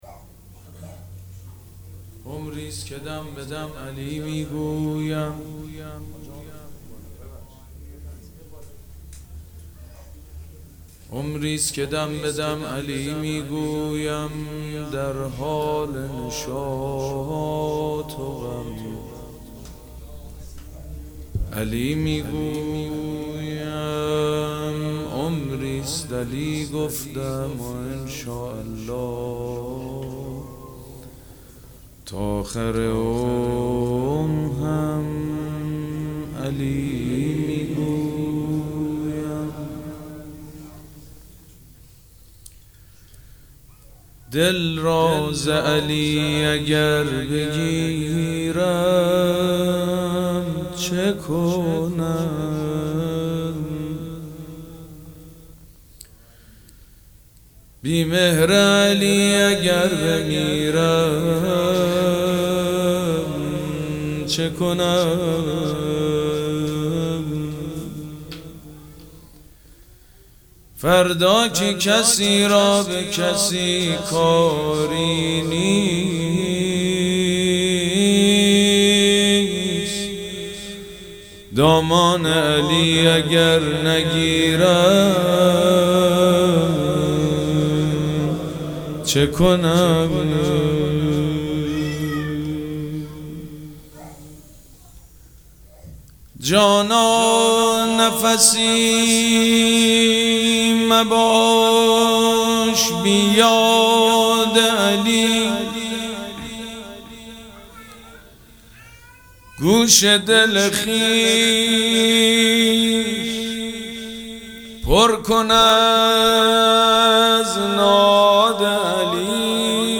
مراسم جشن ولادت حضرت زینب سلام‌الله‌علیها
شعر خوانی
مداح
حاج سید مجید بنی فاطمه